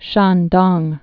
(shändông) also Shan·tung (shăntŭng, shäntng)